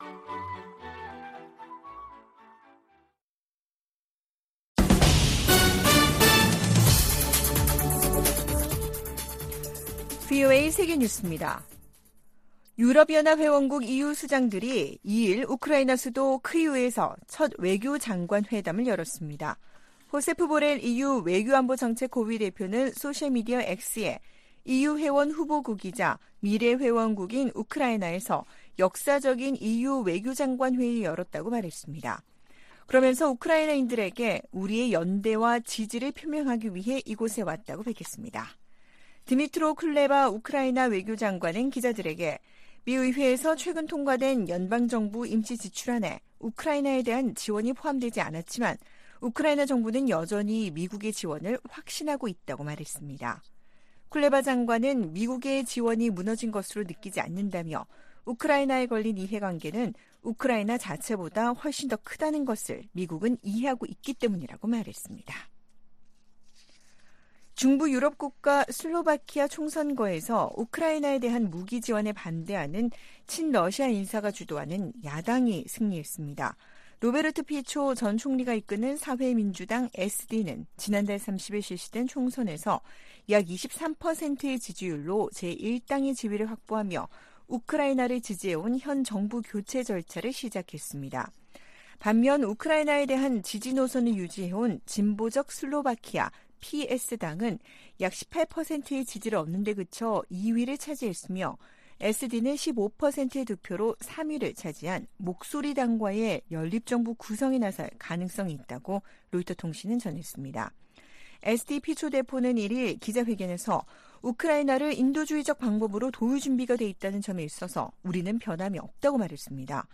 VOA 한국어 아침 뉴스 프로그램 '워싱턴 뉴스 광장' 2023년 10월 3일 방송입니다. 국제원자력기구(IAEA)는 오스트리아에서 열린 제67차 정기총회에서 북한의 지속적인 핵 개발을 규탄하고, 완전한 핵 폐기를 촉구하는 결의안을 채택했습니다. 북한이 핵 보유국 지위를 부정하는 국제사회 비난 담화를 잇달아 내놓고 있습니다.